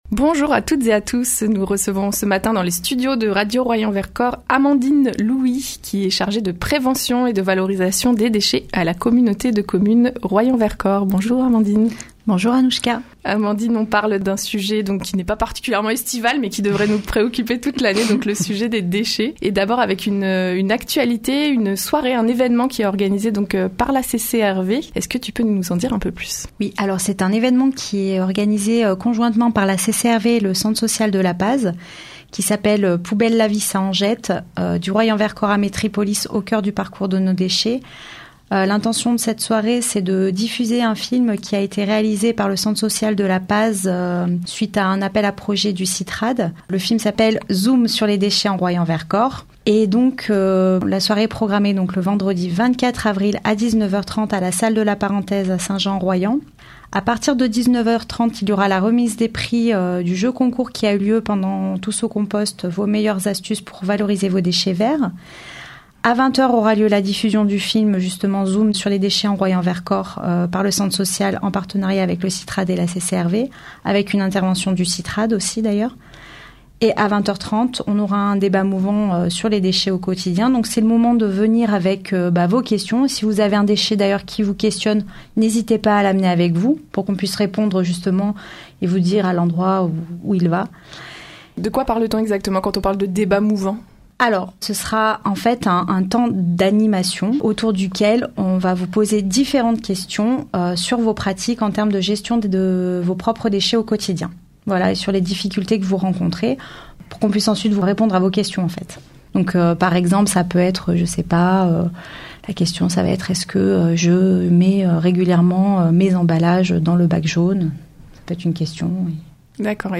Pour nous en parler, nous avons reçu dans nos studios